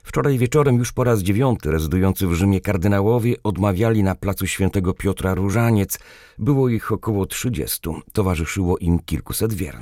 Od 9 dni, co wieczór na placu Św. Piotra zbierają się wierni, by wraz z kardynałami i kapłanami modlić się różańcem w intencji papieża.